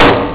gavel.au